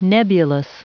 Prononciation du mot : nebulous
nebulous.wav